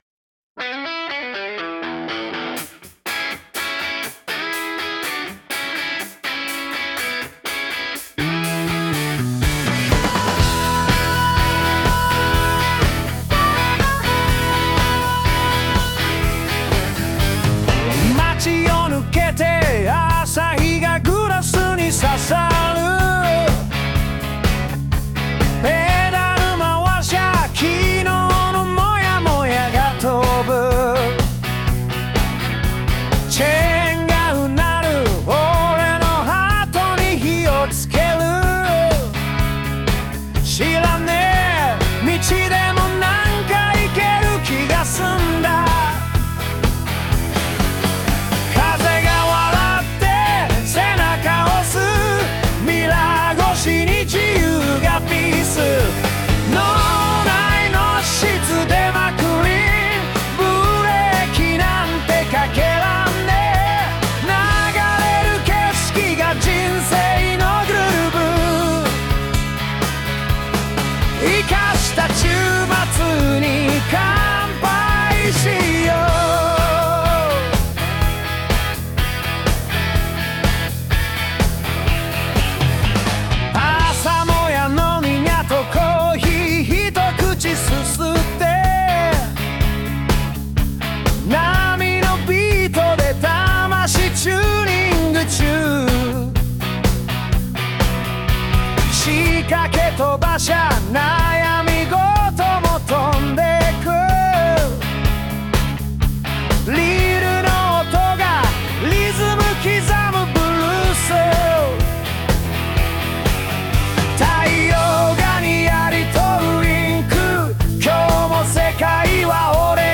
こんな感じでチャッピー先生も気づけばノリノリ状態で、その後何度かやり取りをしながら進めていったんだけど、最後はSunoという別の生成AIのお世話にならなきゃダメみたい。
声は斉藤和義っぽい？